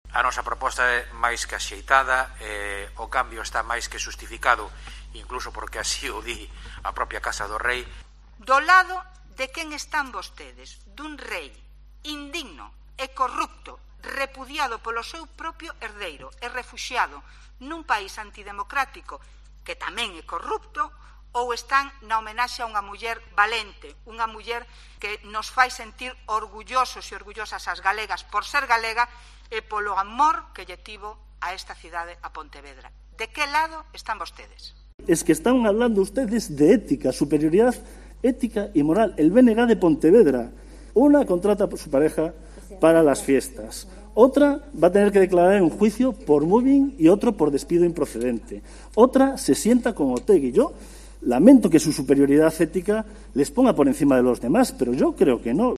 Intervención en pleno de Tino Fernández (PSOE), Carmen Fouces (BNG) y Rafa Domínguez (PP)